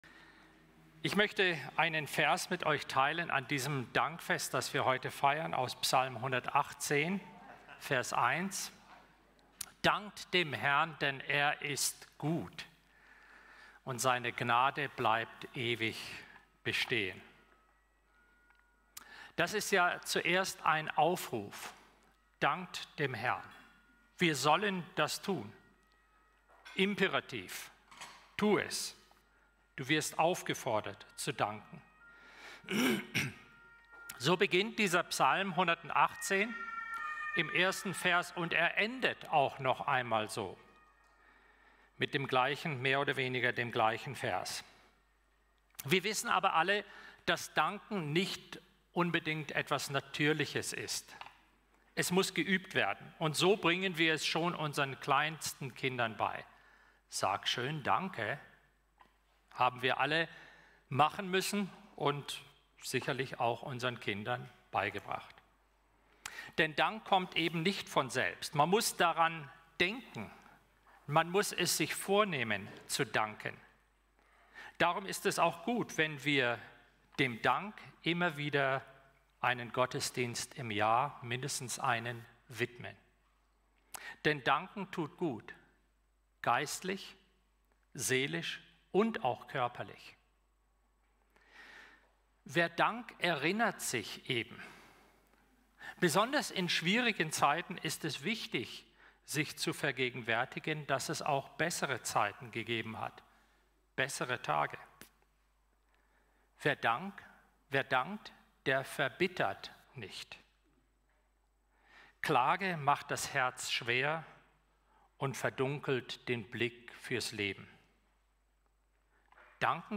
Gottesdienst_-Danket-dem-Herrn-Psalm-118.1.mp3